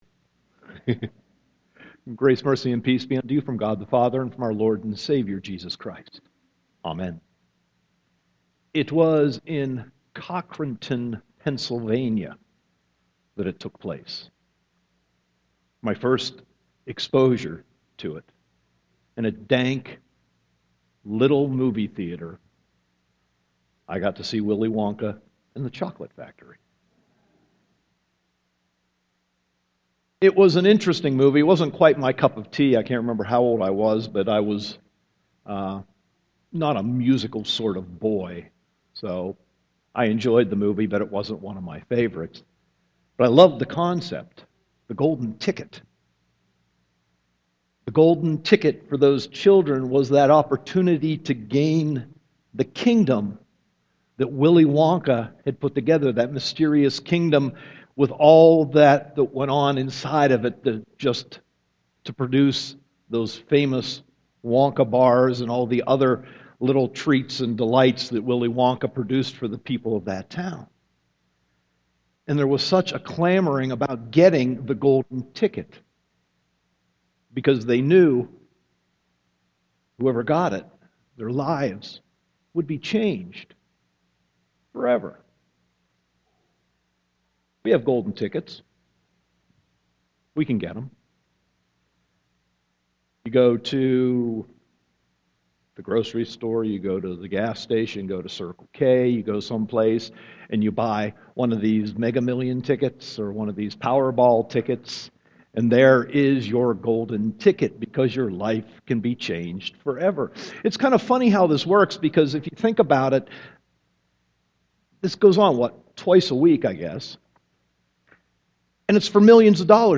Sermon 7.27.2014 -